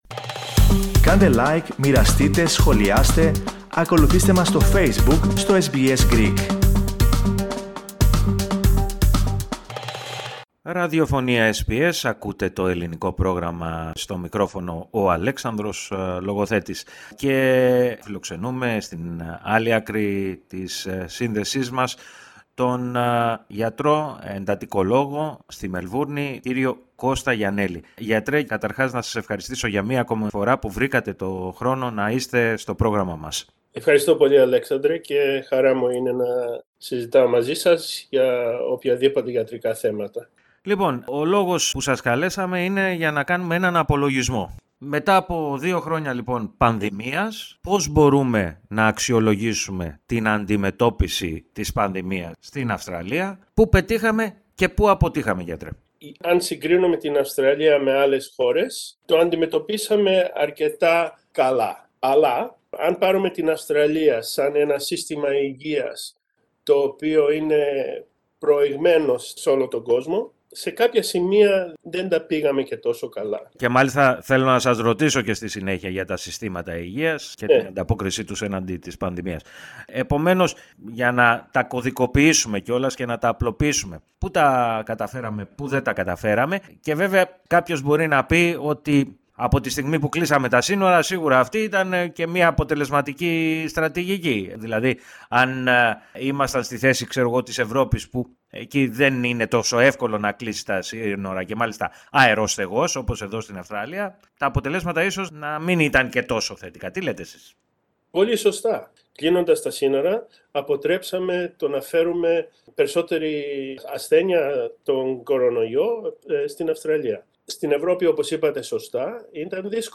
Ακούστε, όμως, ολόκληρη τη συνέντευξη, πατώντας το σύμβολο στο μέσο της κεντρικής φωτογραφίας.